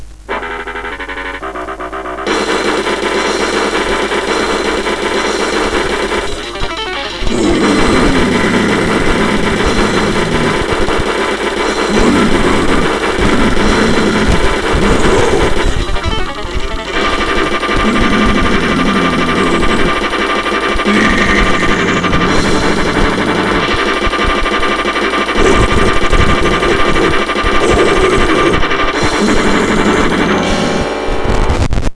vocals/instruments
Hardcore Noise-Grind MP3